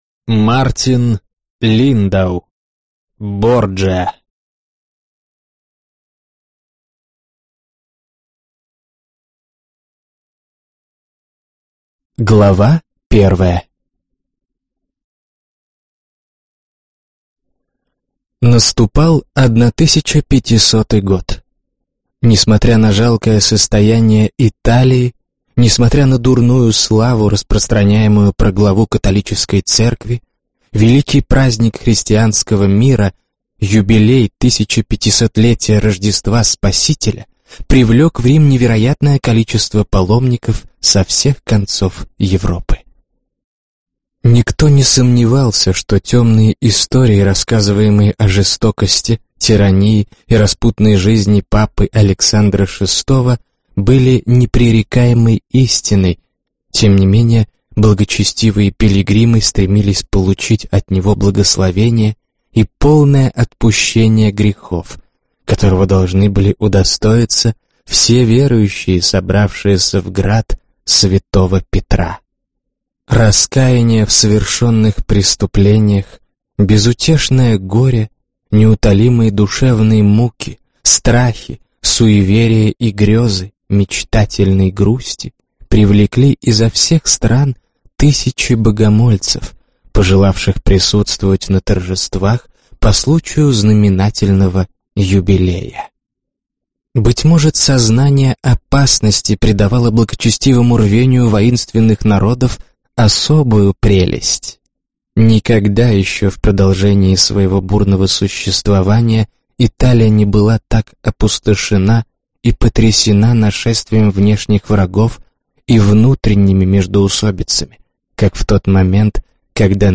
Аудиокнига Борджиа | Библиотека аудиокниг